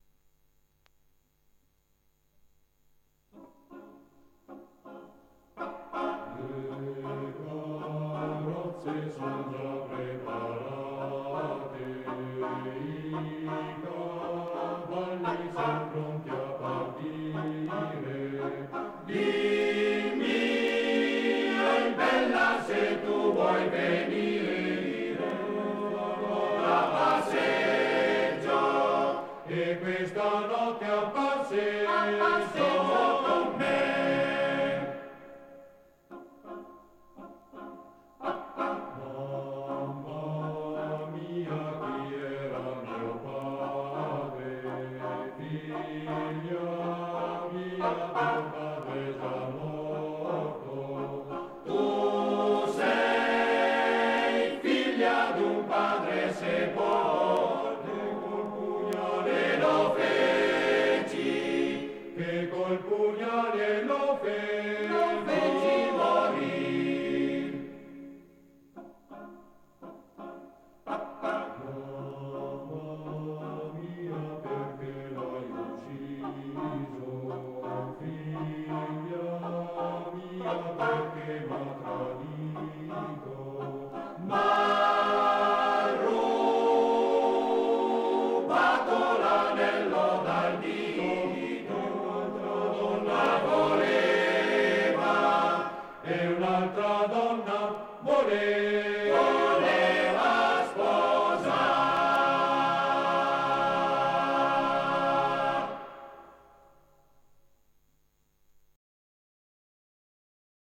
Arrangiatore: Dionisi, Renato
Esecutore: Coro della SAT